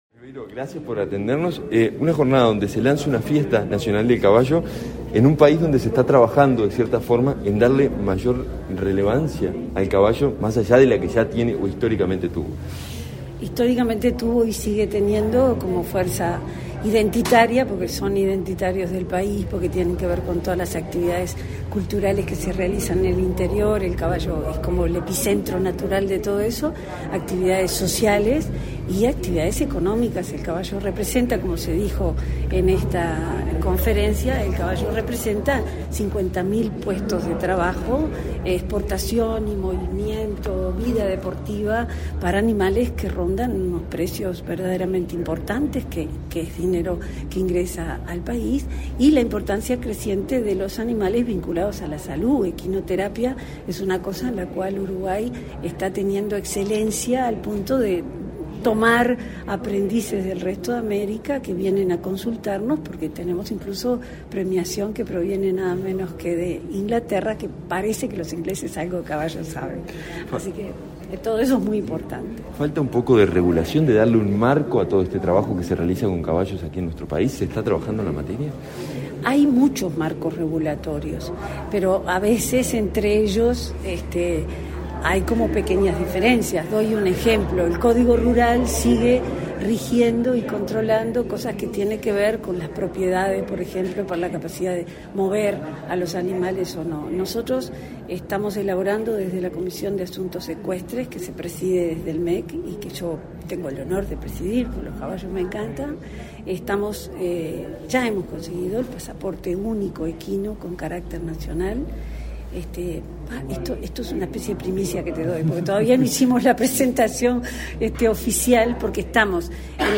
Declaraciones a la prensa de la subsecretaria de Educación y Cultura, Ana Ribeiro
En el marco del Mes de las Tradiciones Criollas, se realizará la 33.ª edición de la Fiesta Nacional del Caballo. Se desarrollará del 11 al 13 de marzo, en la ciudad de Dolores. En la presentación del evento, que será apoyado por los ministerios de Turismo y de Cultura, este 22 de febrero la subsecretaria Ana Ribeiro efectuó declaraciones a la prensa.